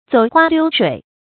走花溜水 注音： ㄗㄡˇ ㄏㄨㄚ ㄌㄧㄨ ㄕㄨㄟˇ 讀音讀法： 意思解釋： 比喻吹牛，說大話。